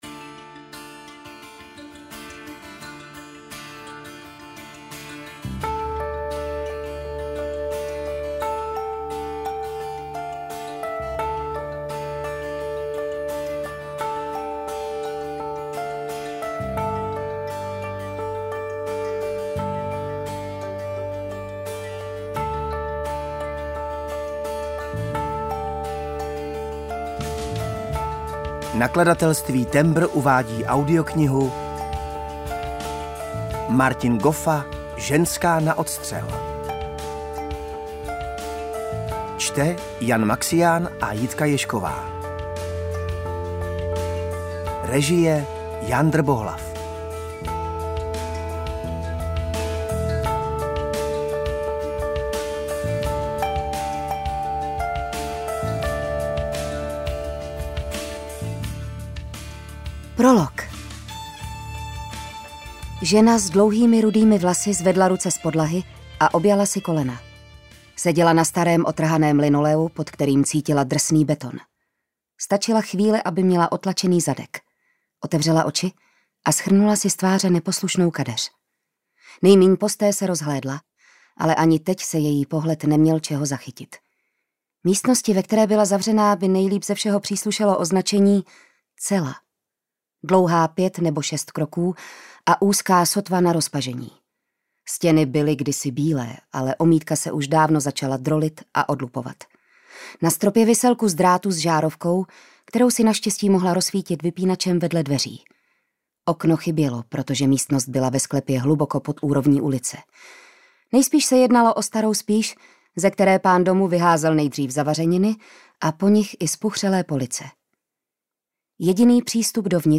UKÁZKA Z KNIHY
audiokniha_zenska_na_odstrel_ukazka.mp3